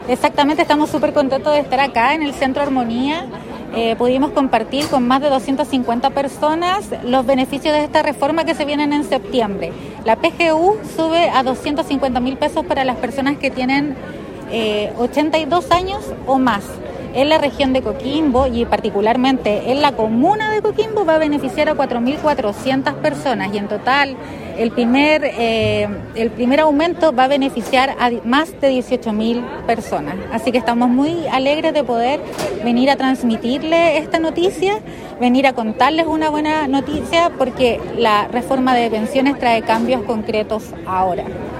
En un encuentro ciudadano con vecinos y vecinas de Coquimbo, se dieron a conocer los beneficios e hitos de la implementación de la nueva ley previsional
En tanto la Seremi del Trabajo y Previsión Social, Monserrat Castro, quien realizó la charla explicativa de la Reforma de Pensiones destacó la cobertura regional que tendrá el primer aumento de la Pensión Garantizada Universal (PGU) y explicó que
Seremi-del-Trabajo-27-08.mp3